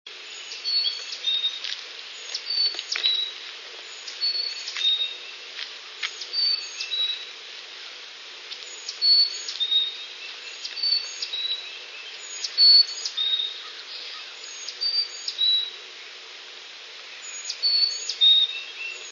Carolina Chickadee
Allaire State Park, Monmouth County, 8/1/99 (60kb) "dee-dee-dee" with Eastern Pewee